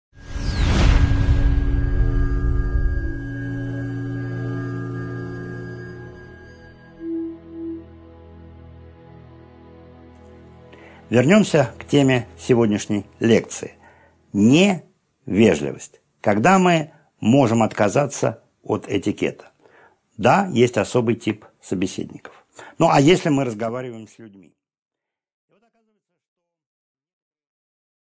Аудиокнига 10.6 Эмоции против этикета | Библиотека аудиокниг